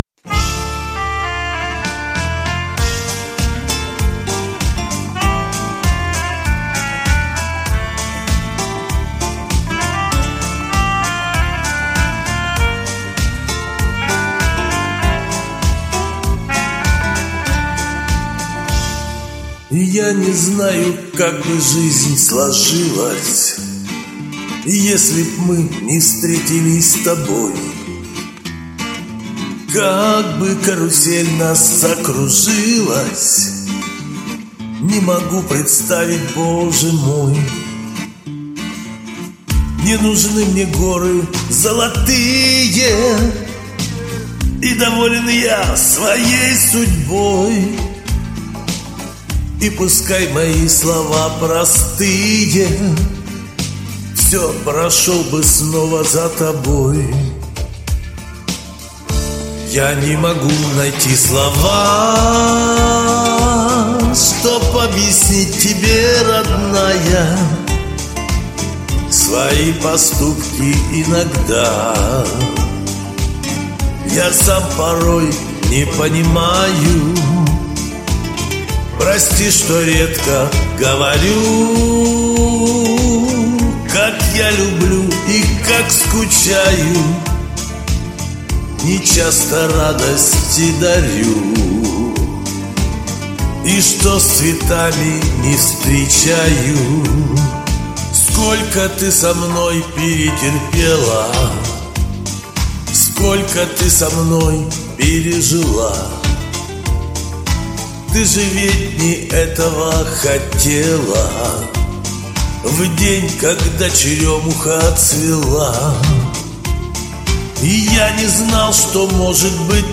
Спели мужики в своем жанре нормально.
Каждый с неповторимым тембром...